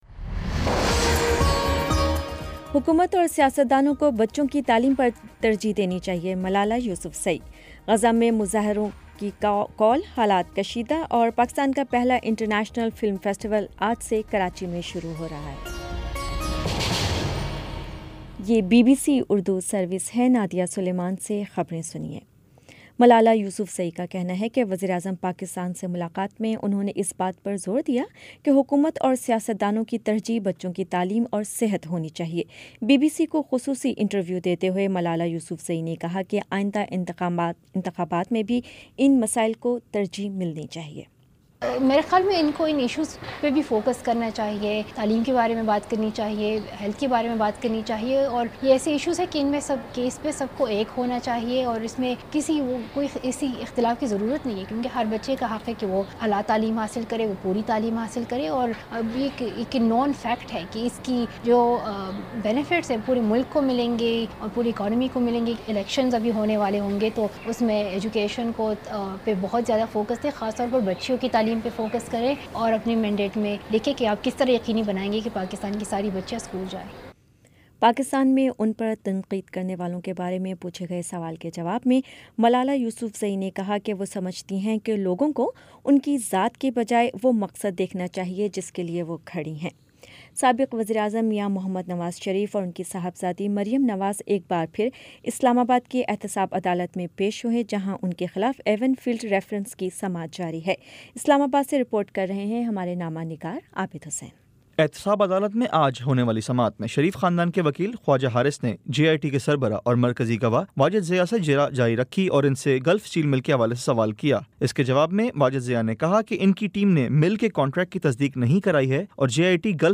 مارچ 30 : شام پانچ بجے کا نیوز بُلیٹن